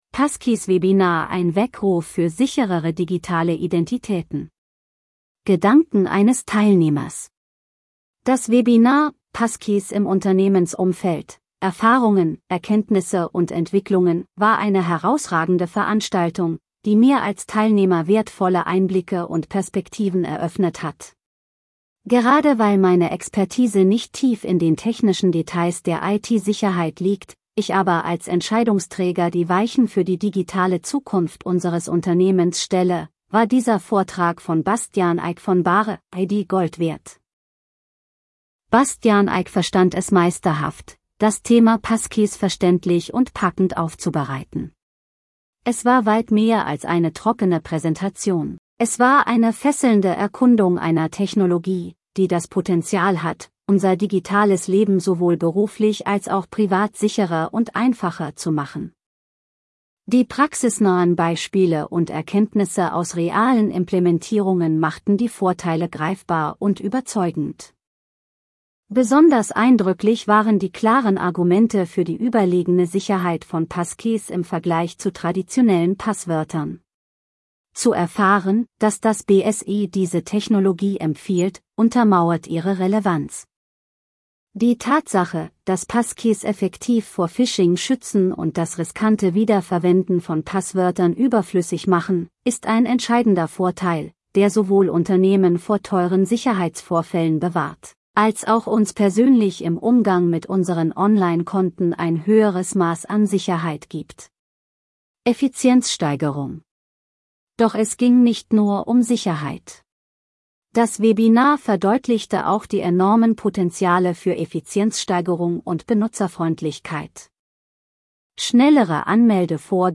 Beitrag gelesen von